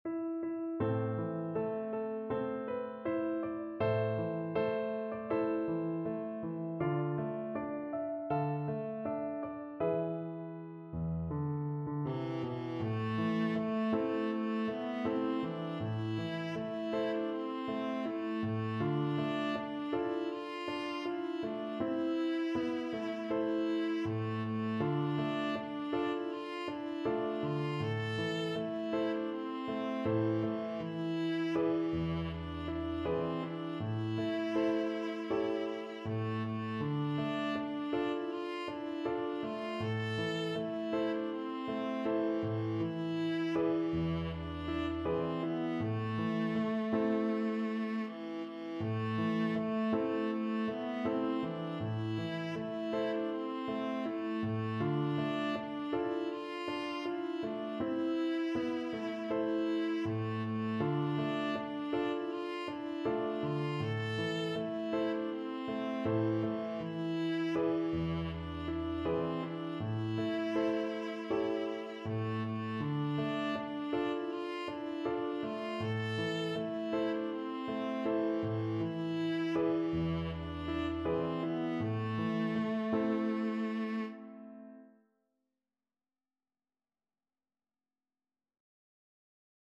Traditional Music of unknown author.
4/4 (View more 4/4 Music)
Andante espressivo